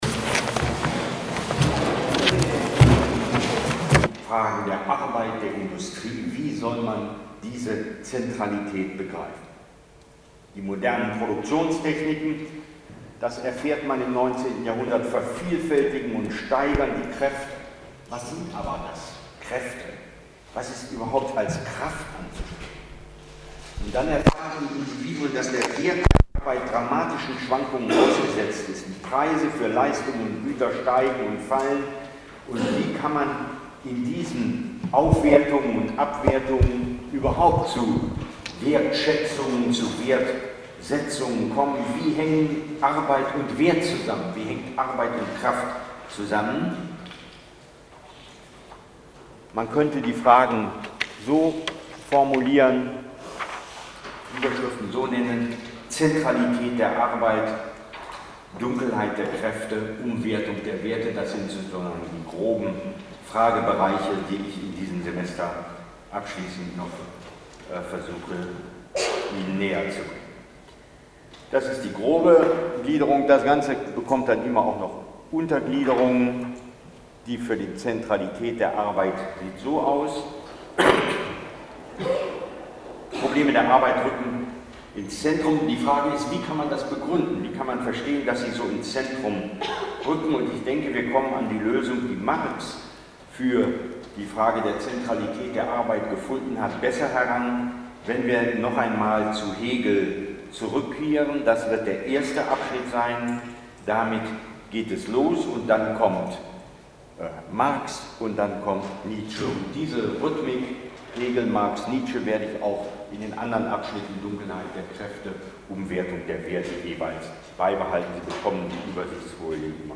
1 30. Vorlesung: VI.